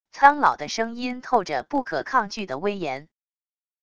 苍老的声音透着不可抗拒的威严wav音频